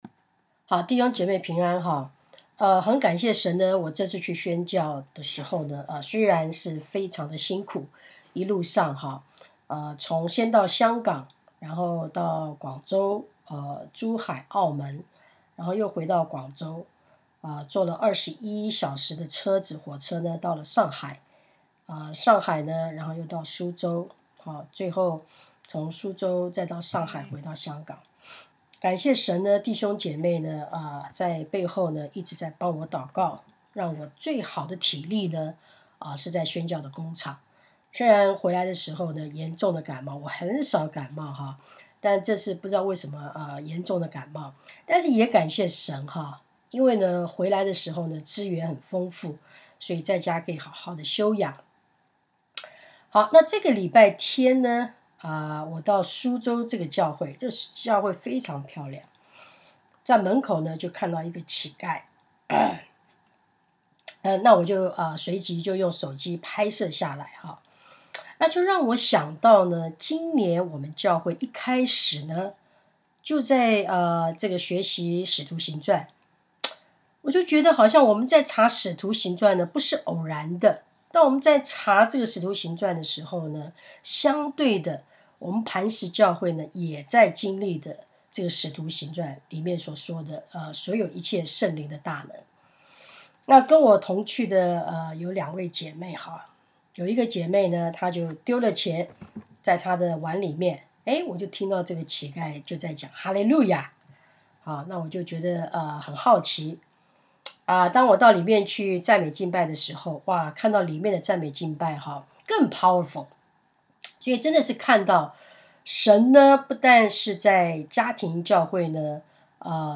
傳道 應用經文 《使徒行傳》3:1-10